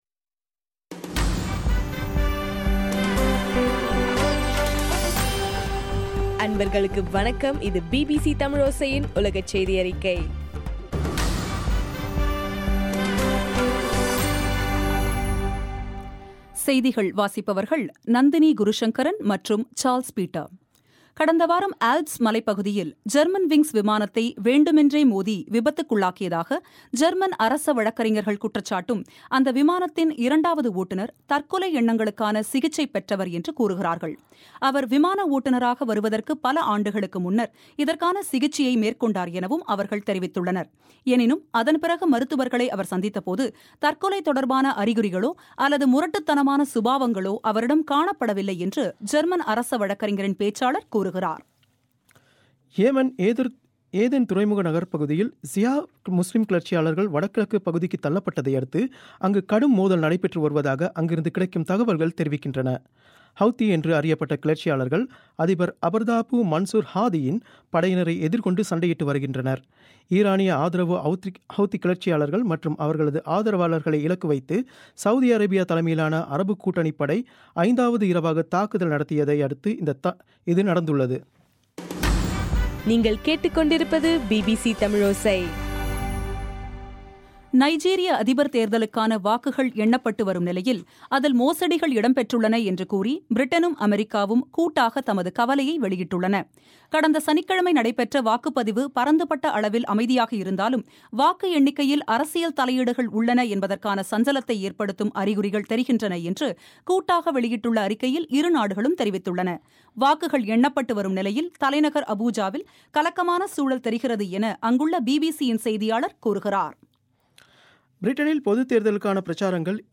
மார்ச் 30 பிபிசியின் உலகச் செய்திகள்